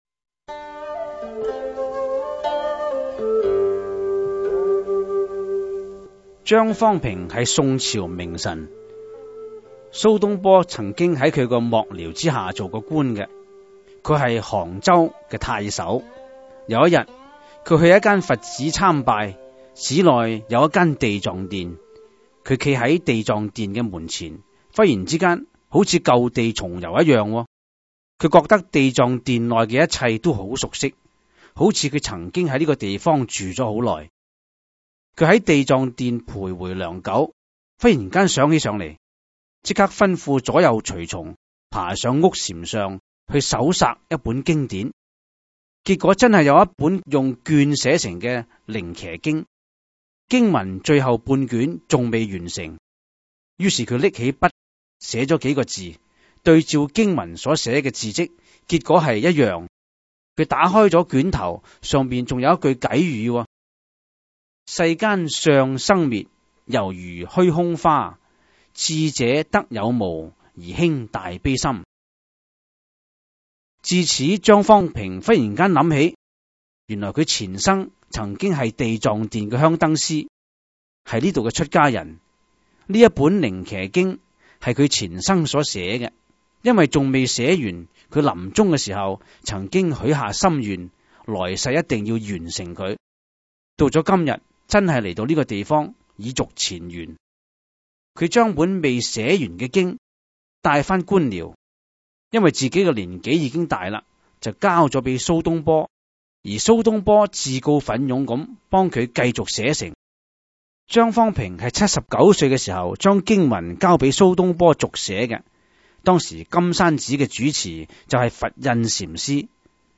第 四 輯               (粵語主講  MP3 格式)